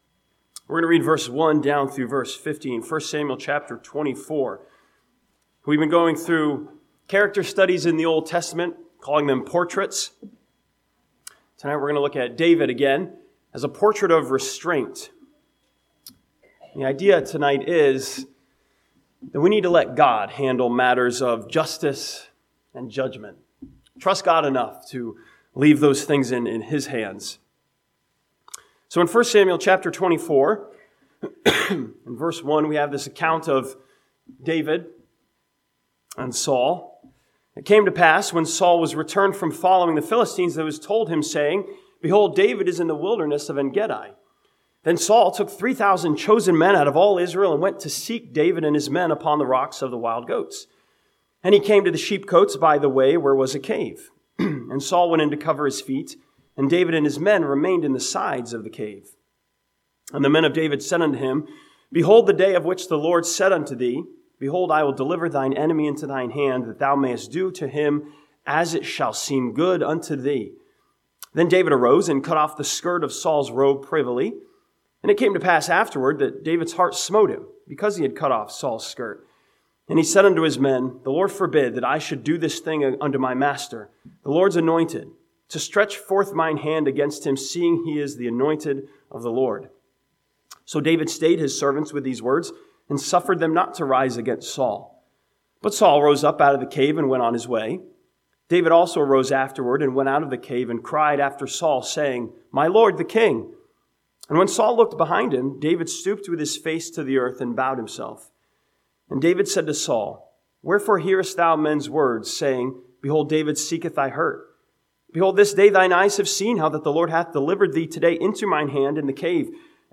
Sunday PM